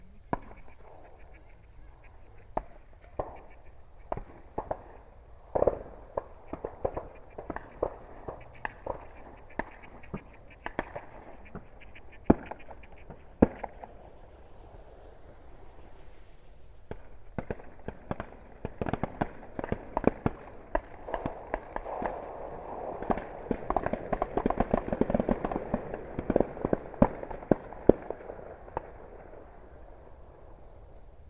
Le compteur à clics utilisé pour évaluer les coups de fusil s’emballe pour atteindre le nombre de 1375 en un peu plus d’une heure qu’a duré la passée du soir (terme qui désigne les vols entre une zone de repos la journée et une zone de nourrissage).
Extrait-ouverture-de-la-chasse-Conde-pour-la-Ligue.mp3